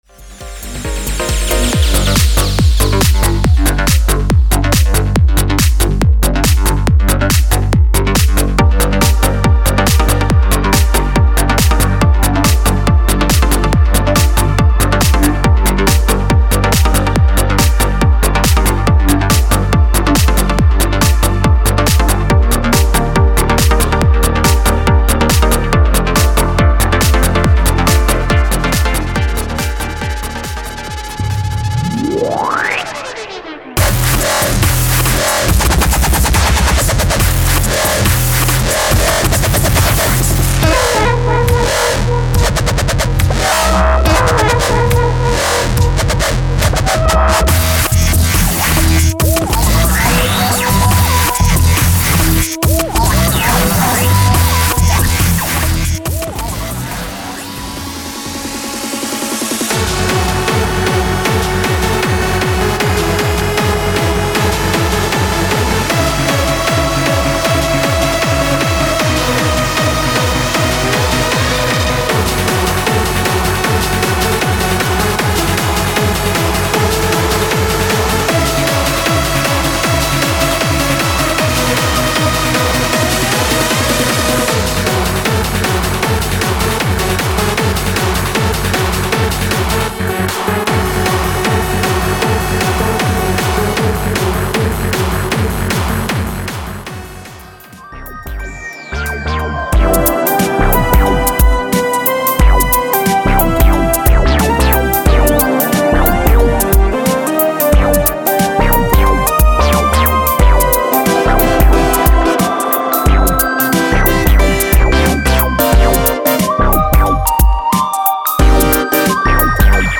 this is a shortened version of four demo clips from